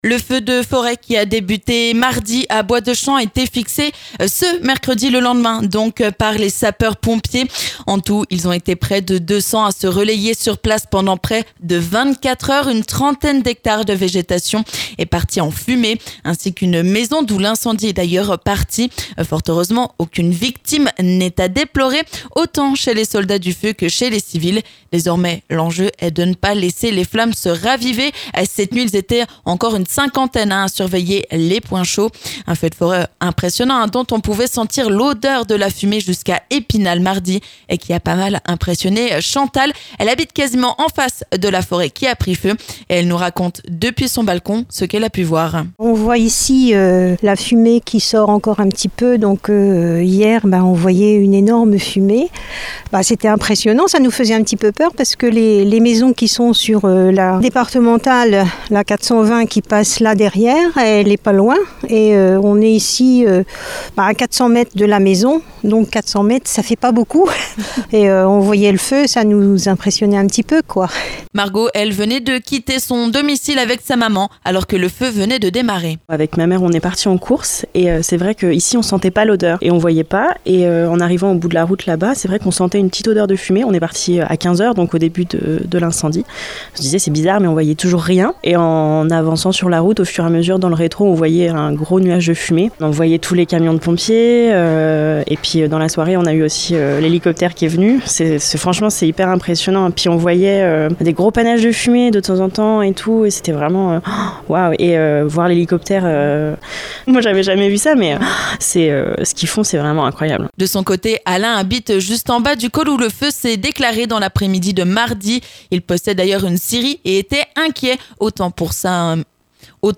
Feu de forêt à Bois-de-Champ : les riverains témoignent après avoir vu les flammes de très près